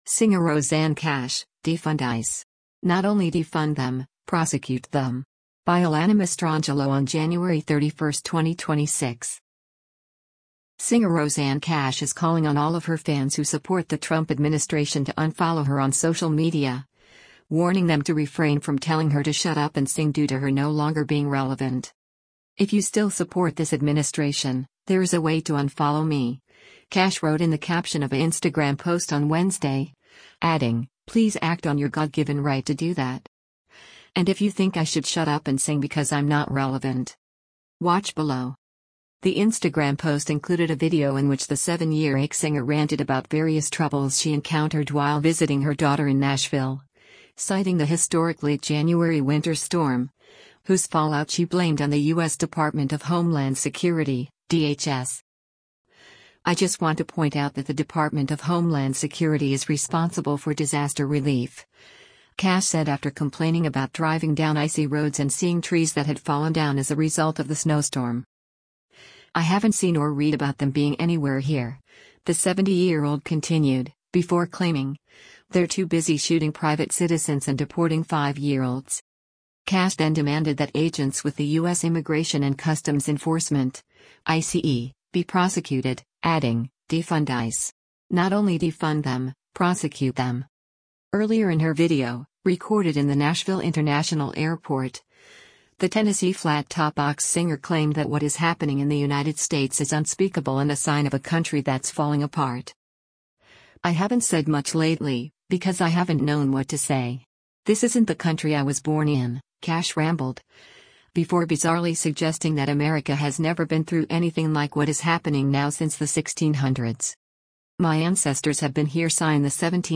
Earlier in her video — recorded in the Nashville International Airport — the “Tennessee Flat Top Box” singer claimed that what is happening in the United States is “unspeakable” and “a sign of a country that’s falling apart.”